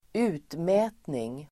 Uttal: [²'u:tmä:tning]